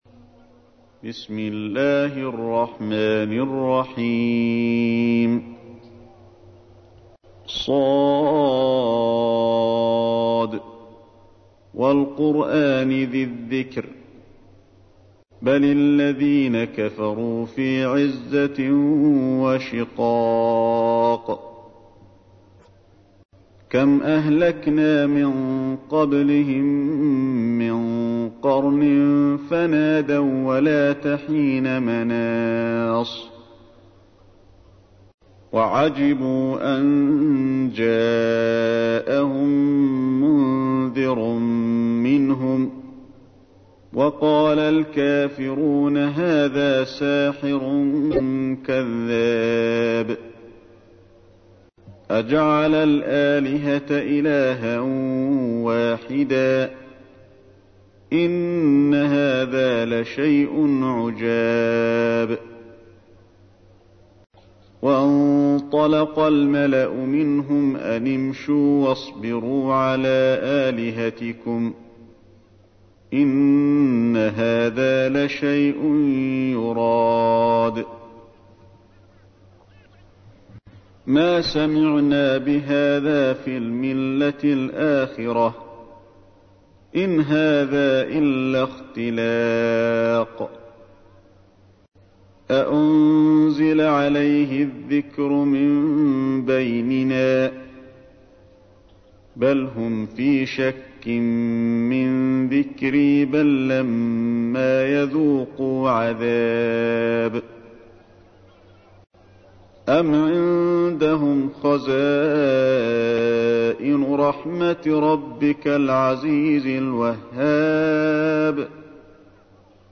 تحميل : 38. سورة ص / القارئ علي الحذيفي / القرآن الكريم / موقع يا حسين